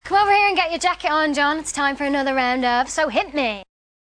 Keavy appeared as a guest presenter on ITV1`s show This Morning.
Some clips have been edited to remove dead air. All crackling/rustling is from the microphones rubbing on clothing.